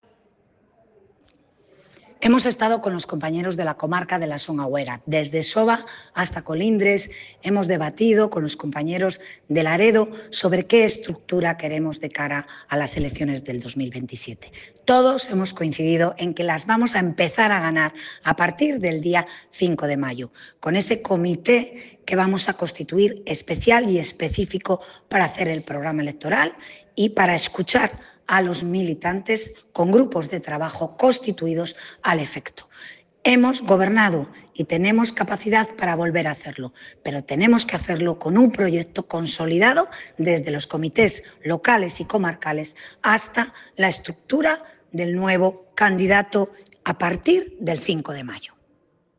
Declaraciones de Paula Fernández Viaña tras el encuentro con militantes en Soba, Laredo y Colindres Nota de prensa encuentro con militantes en Soba, Laredo y Colindres Foto en alta resolución del encuentro en Colindres Foto en alta resolución del encuentro en Laredo